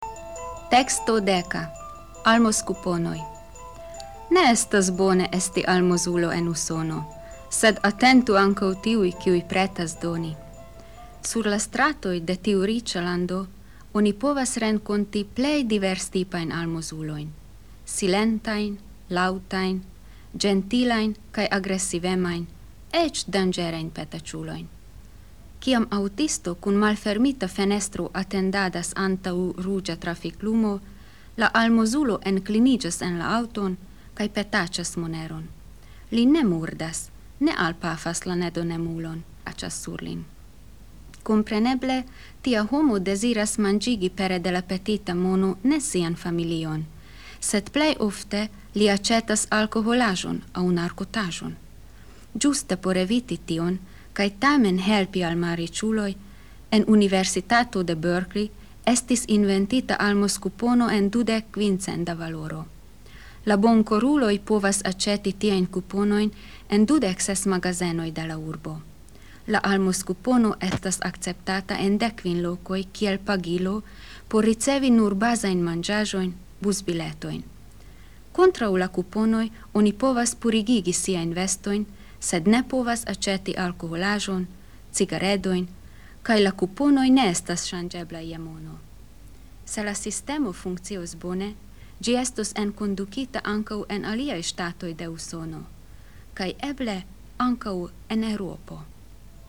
Kategorio: komprena